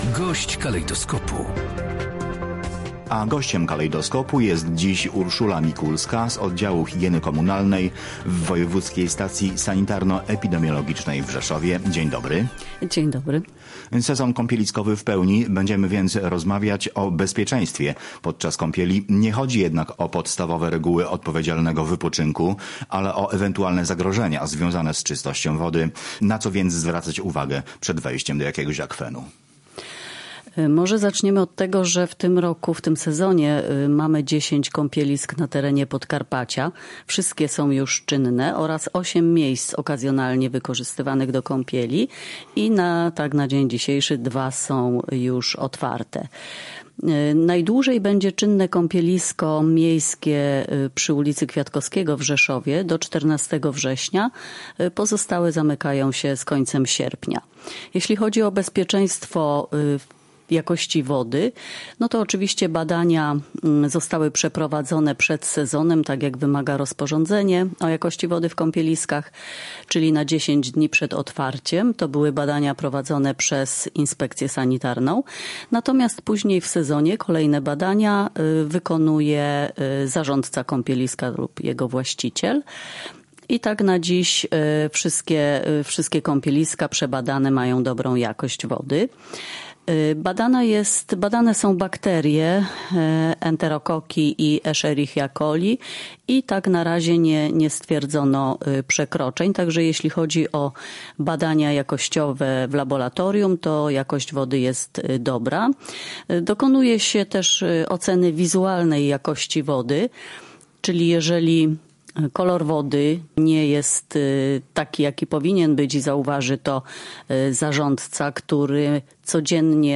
Audycje • - Wybierając się na wypoczynek nad wodą warto sprawdzić czy jest ona czysta i nadaje się do kąpieli